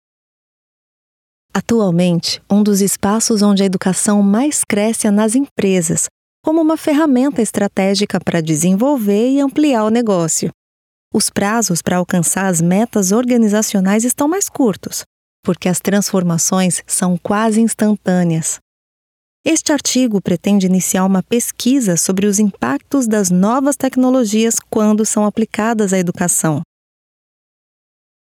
Sprechprobe: eLearning (Muttersprache):
My voice style is natural and conversational, with a neutral accent. My voice is very warm, versatile, conveys credibility, in addition to being jovial, expressive and extremely professional.